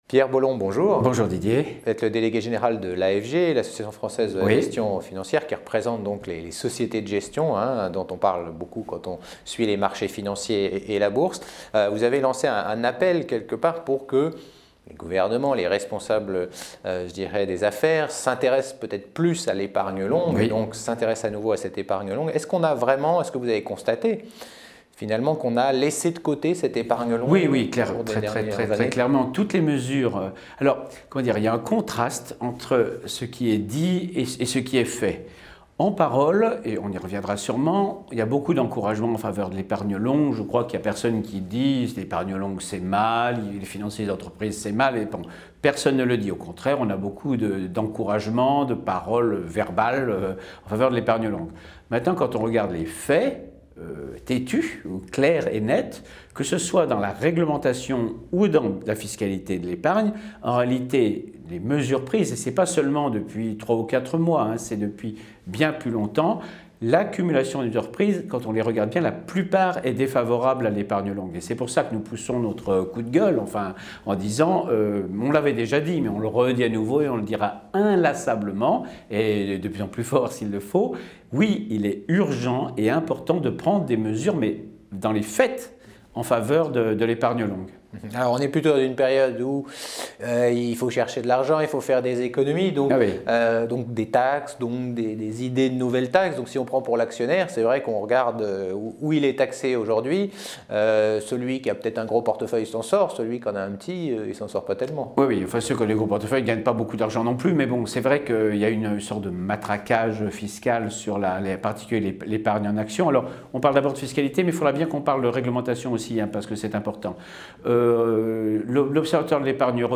Epargne longue : Interview